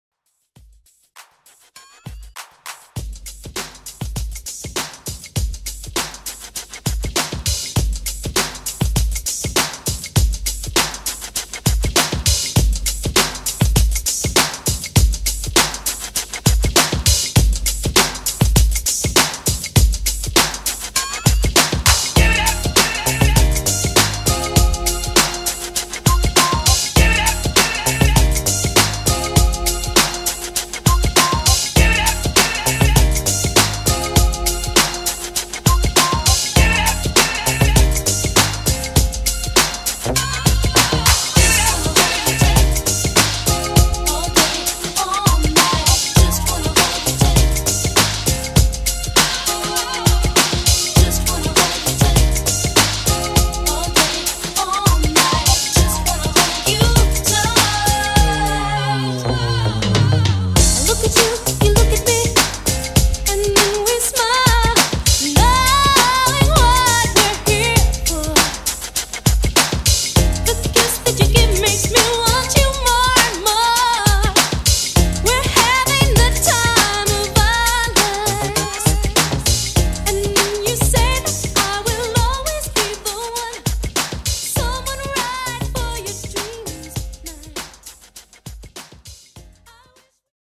90s Dance Pop ReDrum